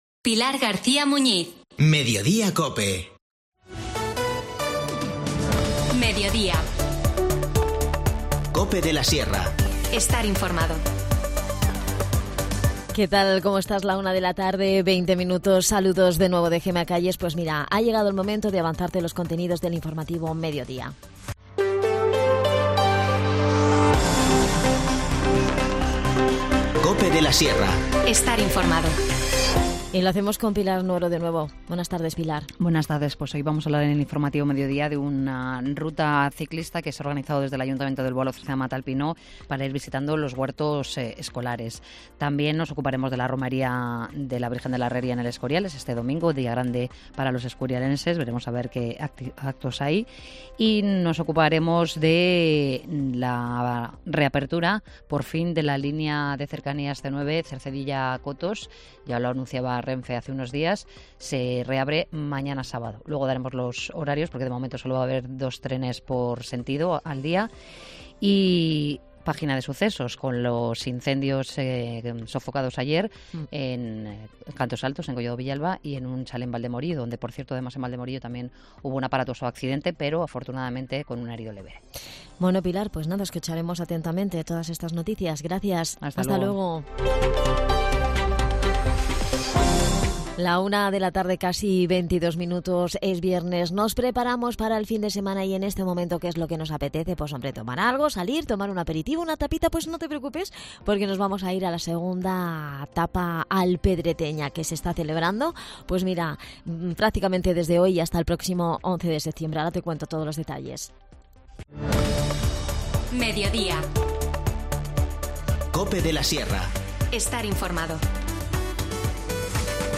Nos cuenta todos los detalles Ana Rosa Piñeiro, concejal de Desarrollo Local